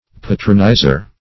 Patronizer \Pa"tron*i`zer\, n.